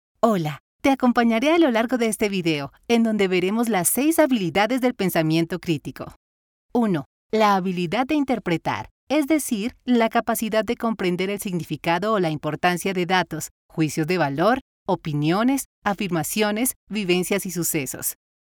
Sprechprobe: eLearning (Muttersprache):